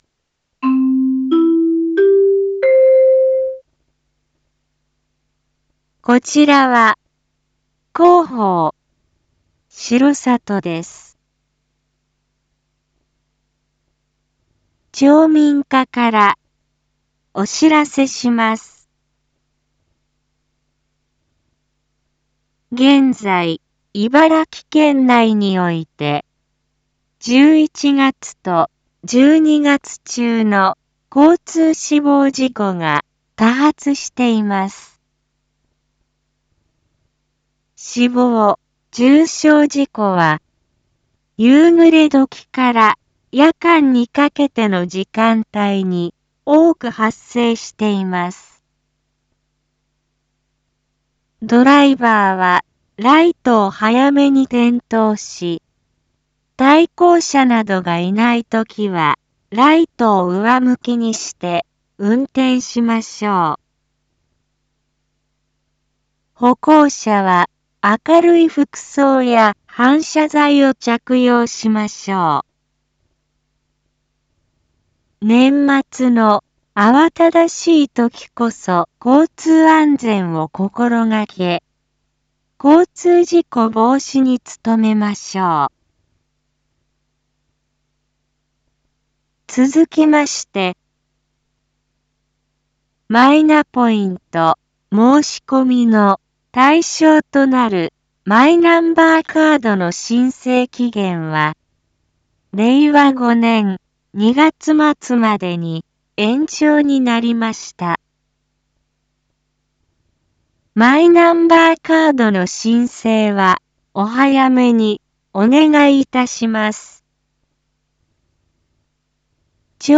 一般放送情報
Back Home 一般放送情報 音声放送 再生 一般放送情報 登録日時：2022-12-26 19:02:21 タイトル：R4.12.26 19時放送分 インフォメーション：こちらは、広報しろさとです。